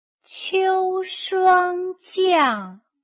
秋霜降/qiū shuāng jiàng/escarcha de otoño